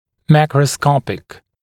[ˌmækrə’skɔpɪk][ˌмэкрэ’скопик]макроскопический, видимый невооружённым глазом